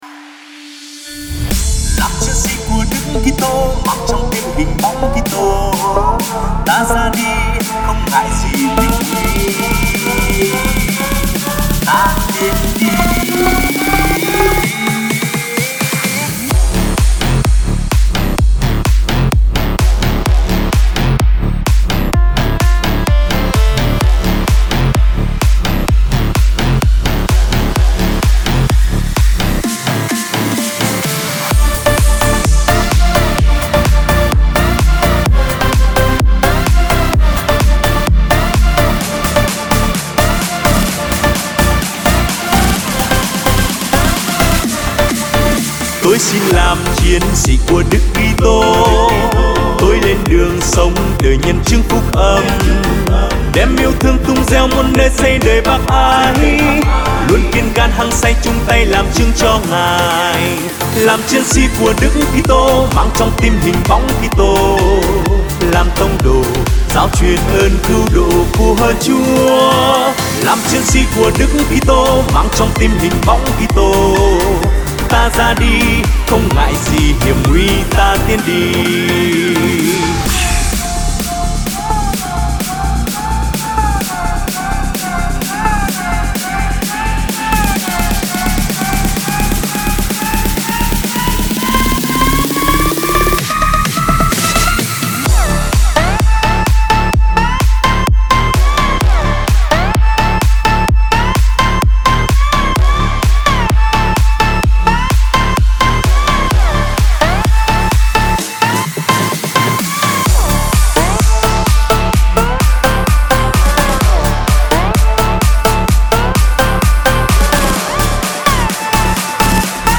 Nhạc Sinh Hoạt Giới Trẻ